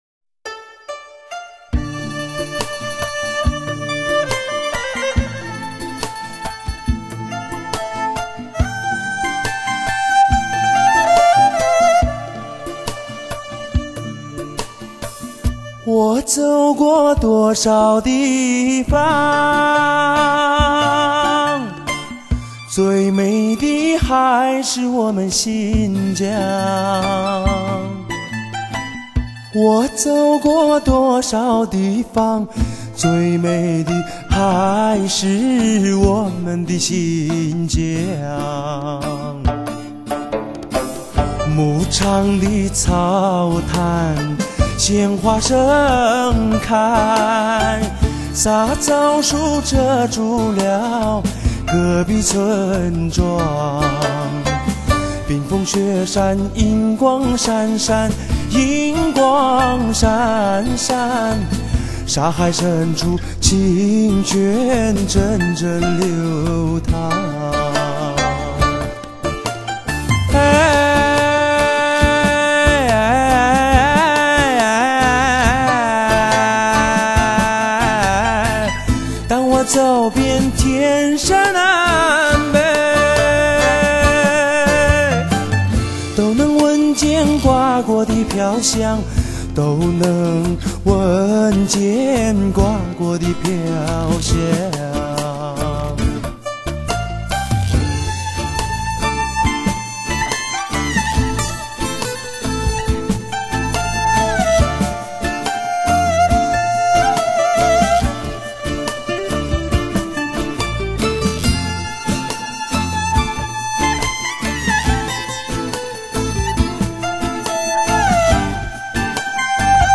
HI-FI勾勒音乐地图
撼动听觉 张扬美声优势